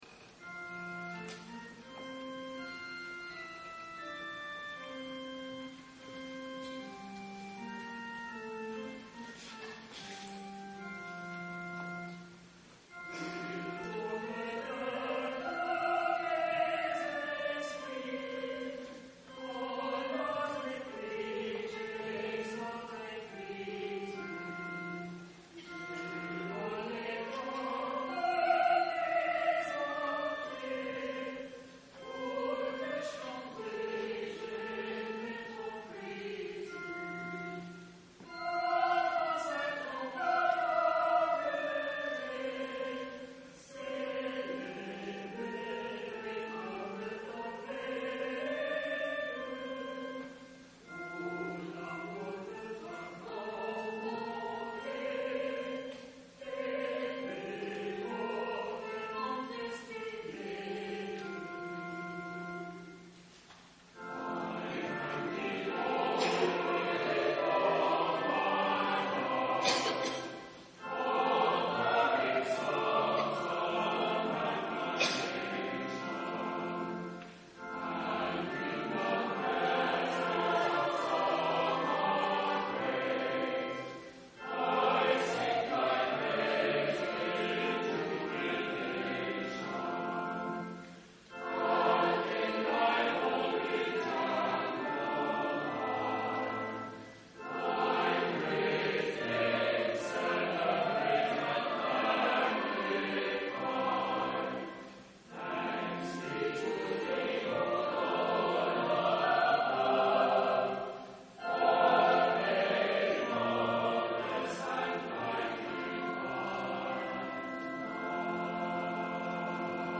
2008 media | Morningside Presbyterian Church
soloists